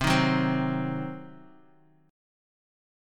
Cdim chord